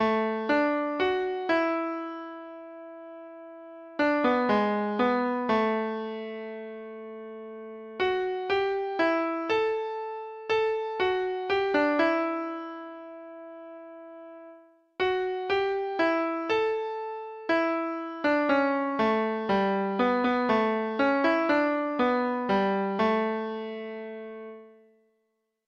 Traditional Music of unknown author.
Reels